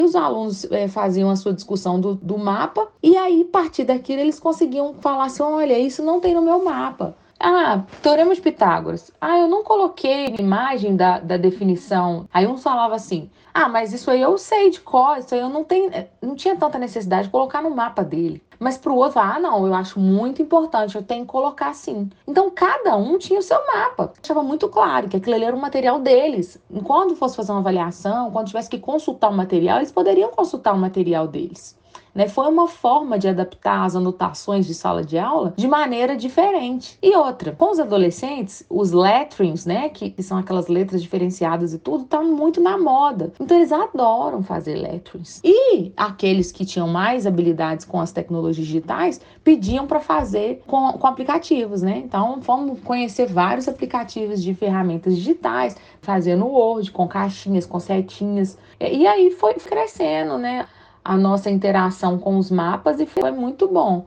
No áudio abaixo, ela explica por que a atividade é tão importante para o desenvolvimento ativo e autônomo dos estudantes: